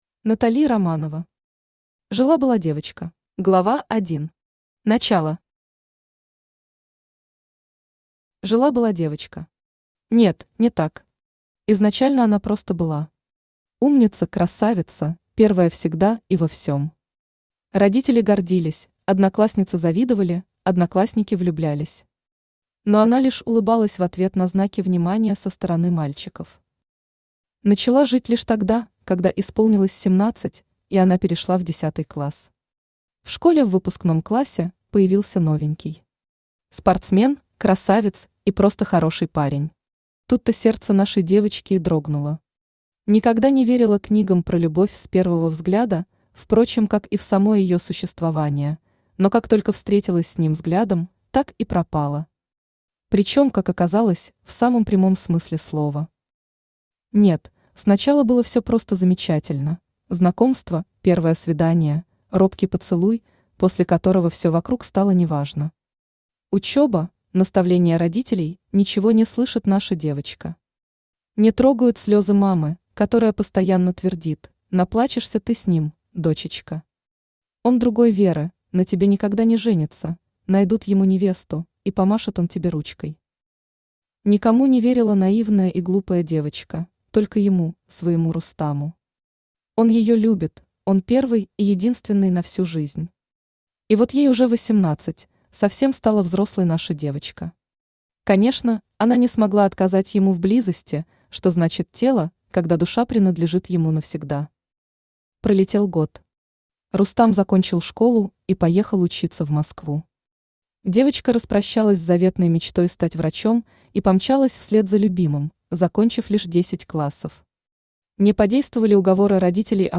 Аудиокнига Жила-была девочка | Библиотека аудиокниг
Aудиокнига Жила-была девочка Автор Натали Романова Читает аудиокнигу Авточтец ЛитРес.